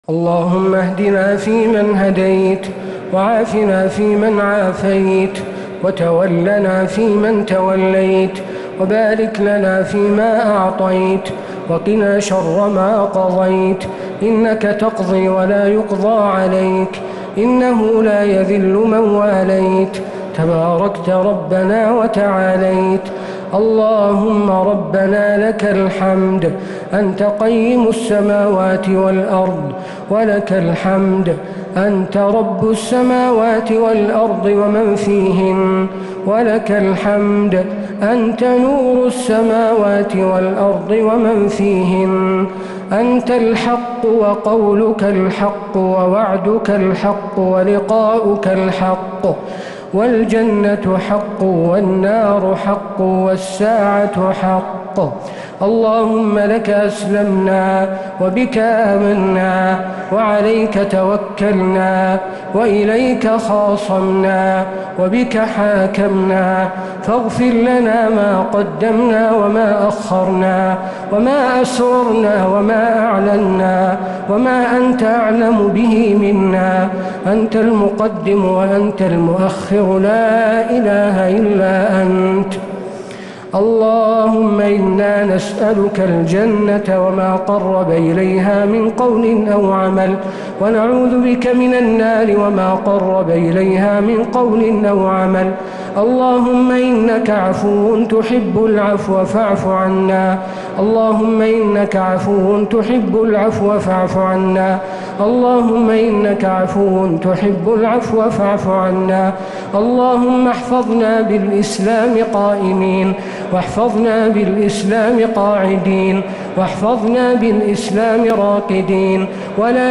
دعاء القنوت ليلة 6 رمضان 1446هـ | Dua 6th night Ramadan 1446H > تراويح الحرم النبوي عام 1446 🕌 > التراويح - تلاوات الحرمين